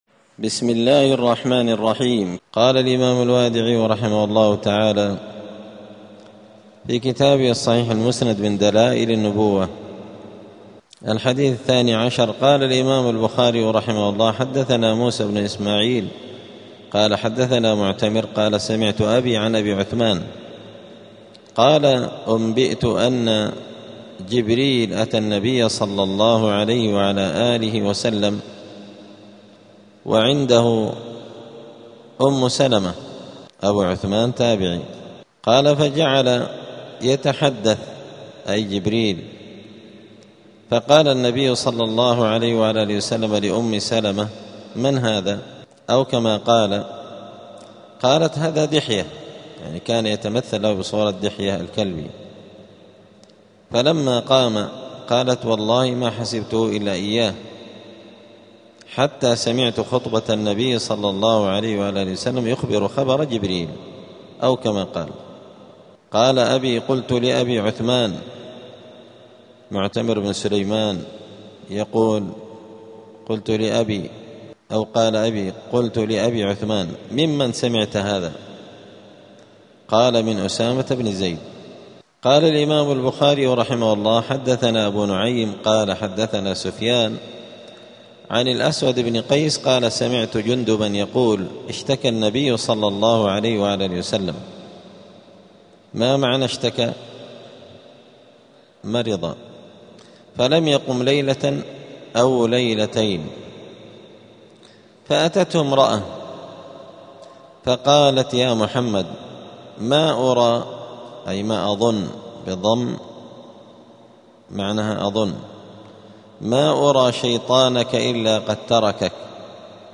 *الدرس السابع (7) {فصل في كيفية الوحي وأنواعه}.*